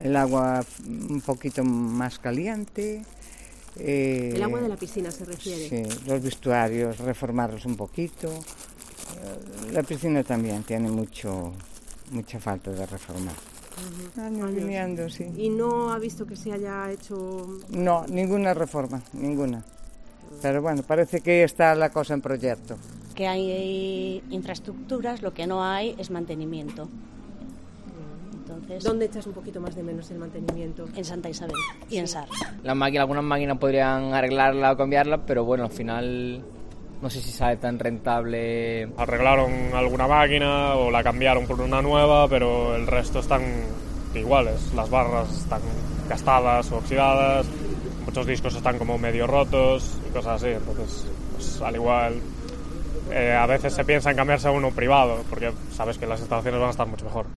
En Santa Isabel es donde hay más quejas por el estado de las instalaciones: hay usuarios que llevan años esperando por reformas que no dan llegado: "El agua debería estar un poquito más caliente, los vestuarios hay que reformarlos", nos reconoce una mujer.